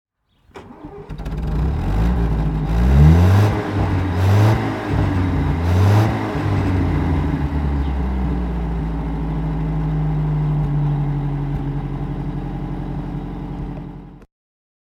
Motorsounds und Tonaufnahmen zu MG Fahrzeugen (zufällige Auswahl)
MG 1 1/2 Litre Folding Head Foursome (1938) - Starten und Leerlauf